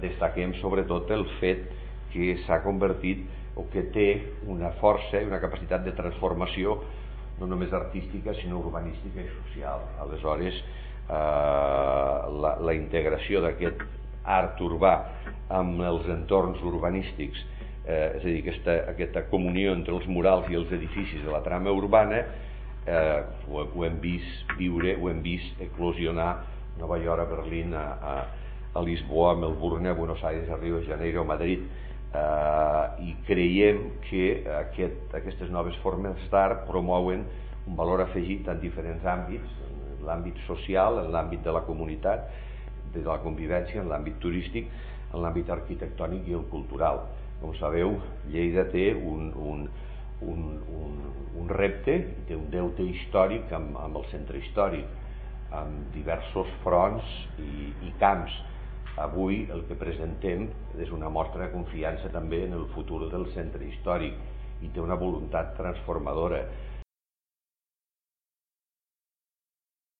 tall-de-veu-de-lalcalde-de-lleida-miquel-pueyo-sobre-la-primera-edicio-del-lleida-_potfest